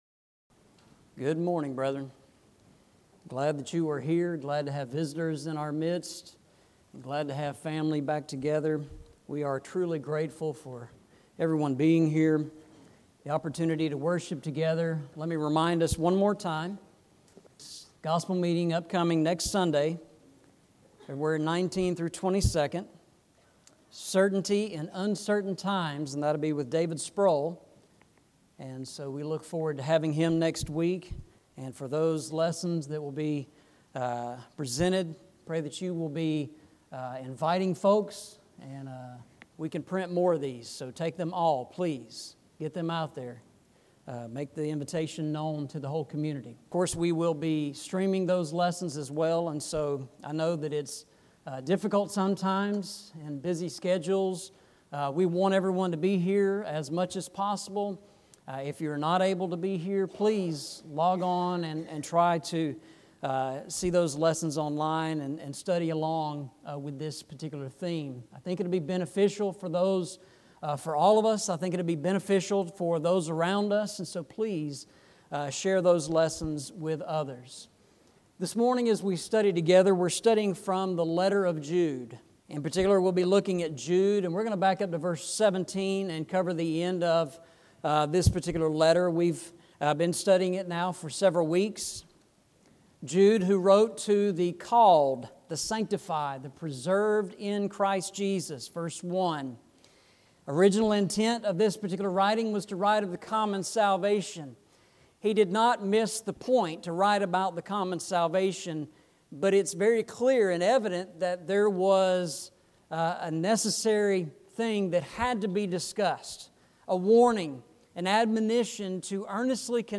Eastside Sermons
Jude 20-25 Service Type: Sunday Morning « Tract Sermon & Review